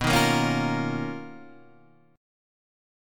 BM#11 chord